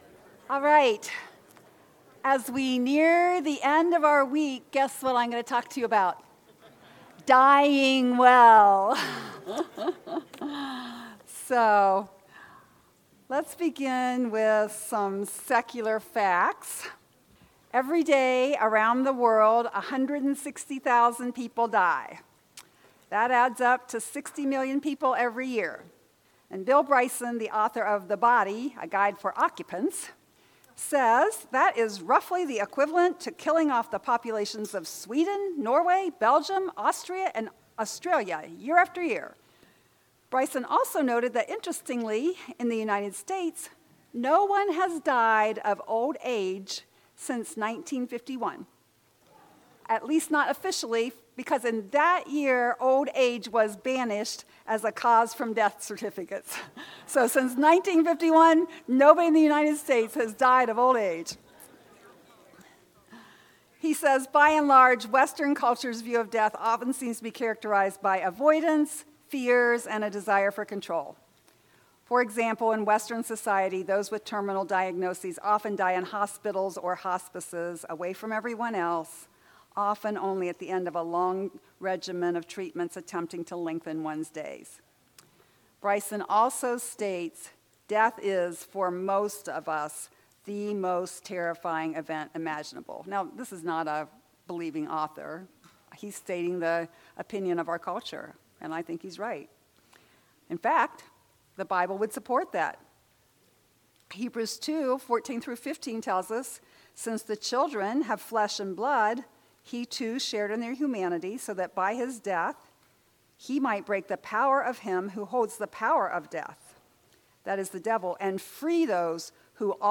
This is a session from the Biblical Counseling Training Conference hosted by Faith Church in Lafayette, Indiana. This session addresses the struggles that children who have been sexually or physically abused carry into adulthood.